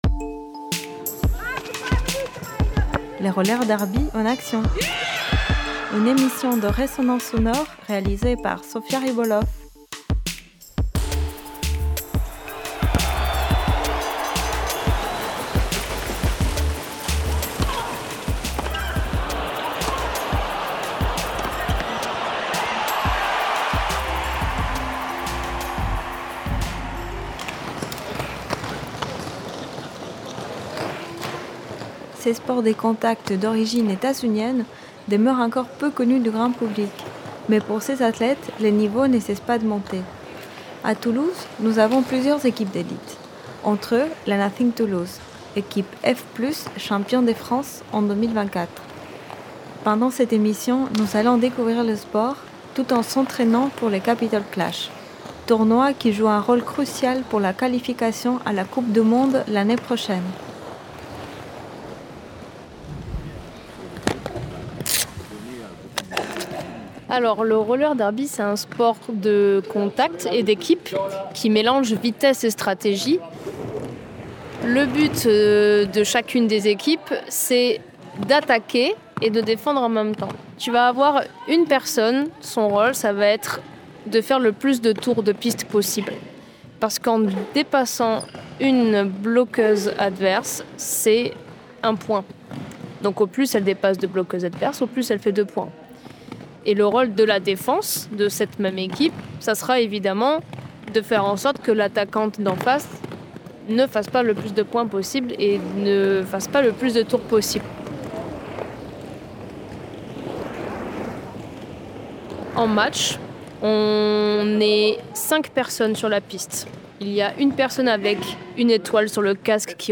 LeRollerDerby_enAction_Mix_PAD_OK.mp3